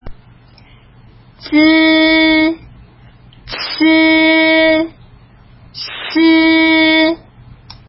舌歯音
z(i) (無気音)舌先を下の歯の裏にあて、口を軽く横に開き、舌先と歯の間から弱い息で｢ヅー｣と発音するイメージ。
c(i) (有気音)舌先を下の歯の裏にあて、口を軽く横に開き、舌先と歯の間から強い息で｢ツー｣と発音するイメージ。
s(i) 舌先を下の歯の裏に近づけ、口を軽く横に開き、舌先と歯の間から｢スー｣と発音するイメージ。